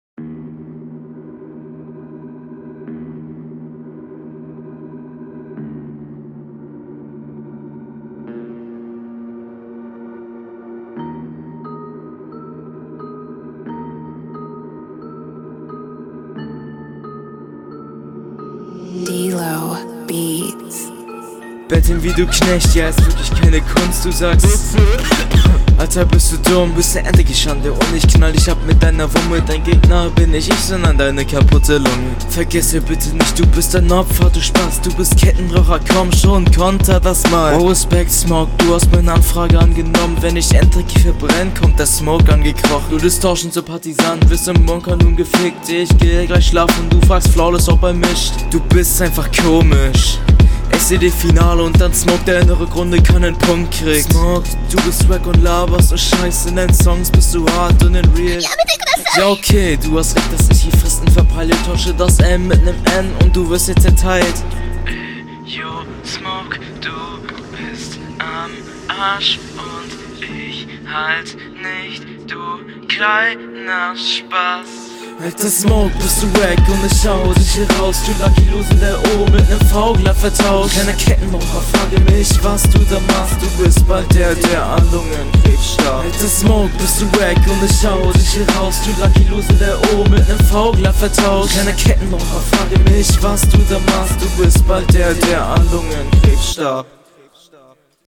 Hinrunde 1
- Mix fühl' ich überhaupt nicht. Komische Peaks drin und die Doubles sind nicht on …